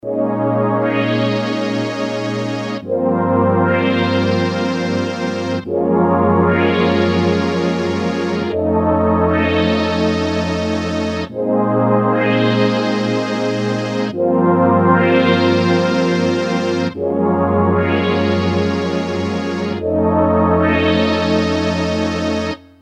synth pad
demo delta Midi GM file